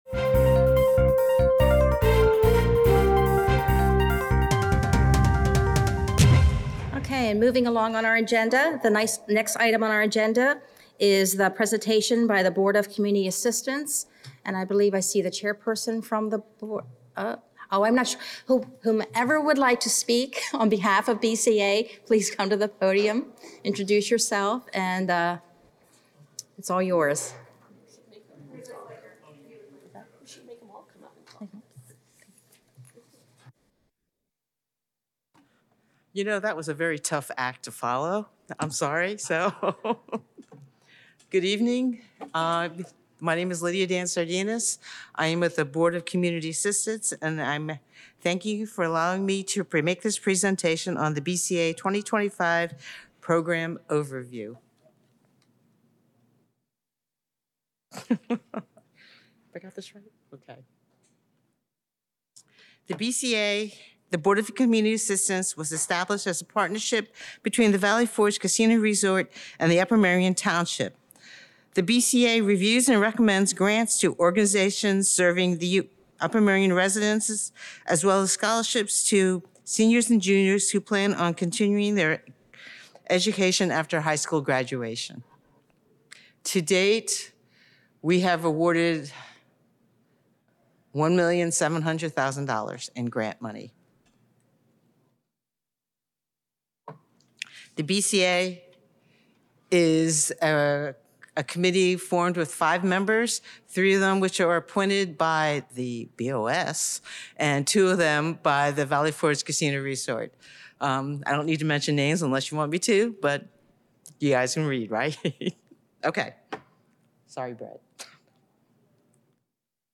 New Brief - Presentation by the Board of Community Assistance